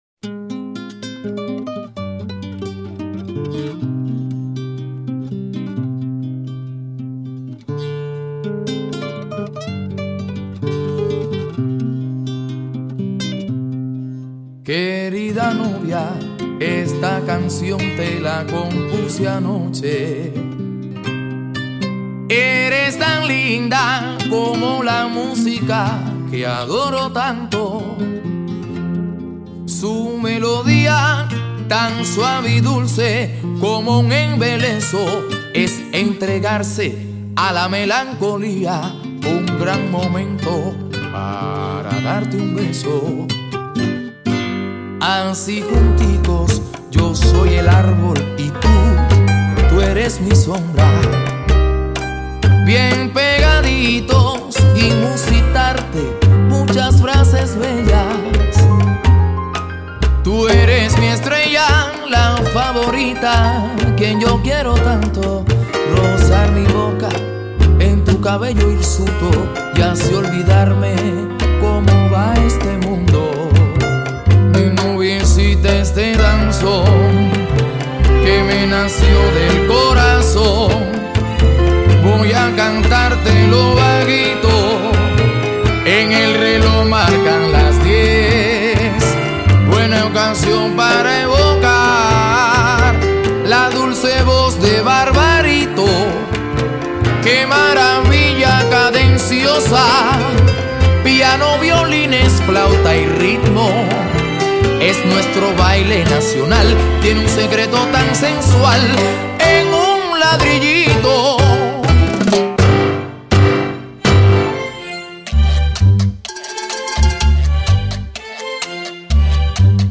хоть и не совсем это дансон.
Ее и слушать приятно и голосить.